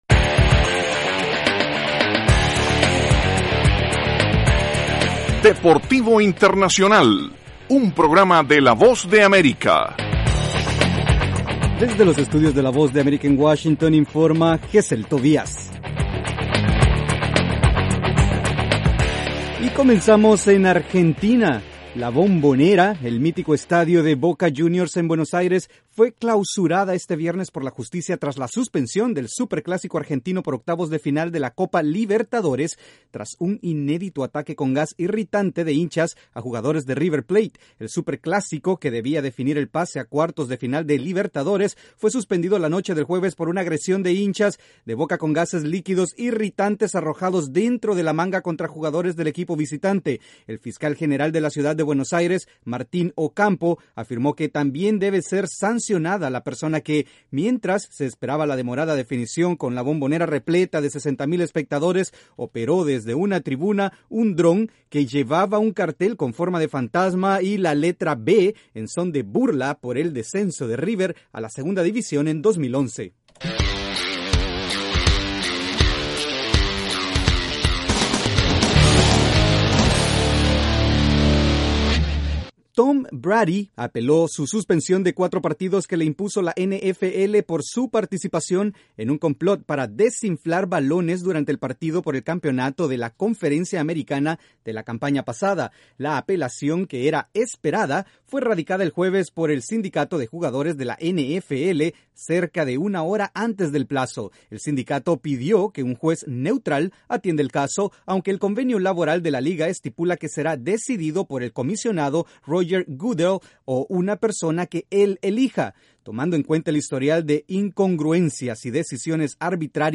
Escuche un resumen con las noticias más importantes del mundo deportivo. Desde los estudios de la Voz de América en Washington